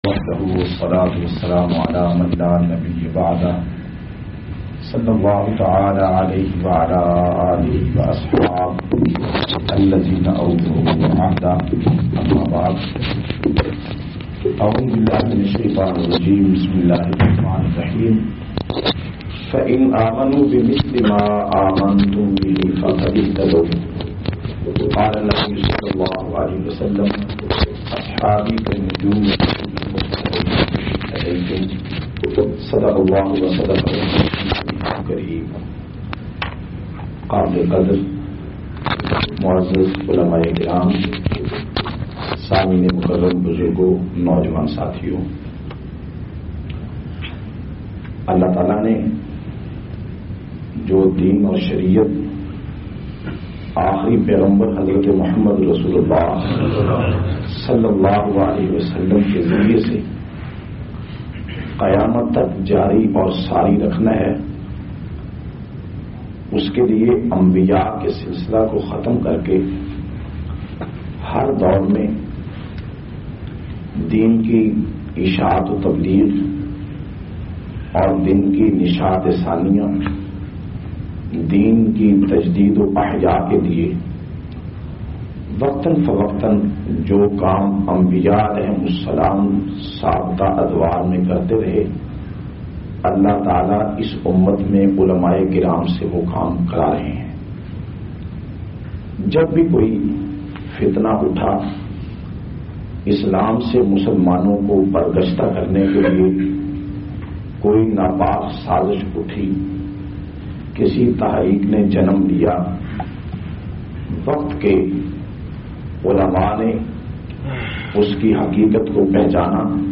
670- Ulma e Deen ki Ahmiyat Masjid Naqeeb e Islam,Barmingham,UK.mp3